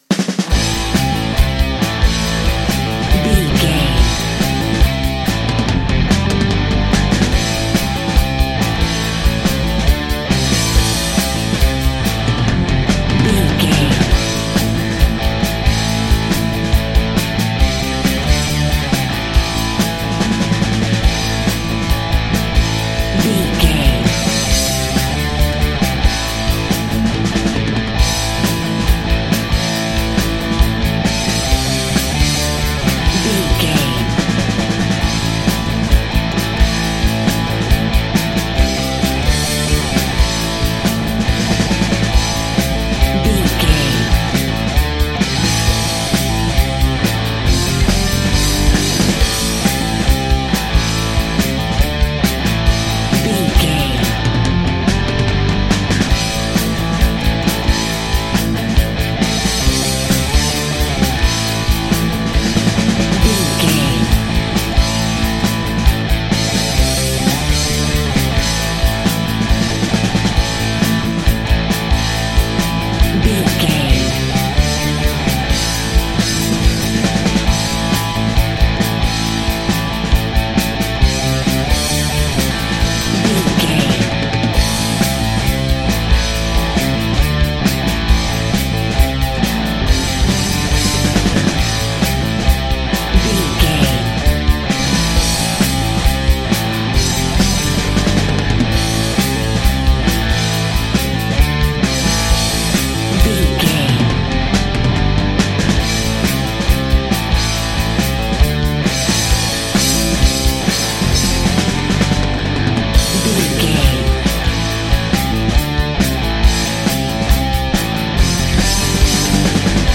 Ionian/Major
DOES THIS CLIP CONTAINS LYRICS OR HUMAN VOICE?
electric guitar
drums
bass guitar
hard rock
aggressive
energetic
intense
nu metal
alternative metal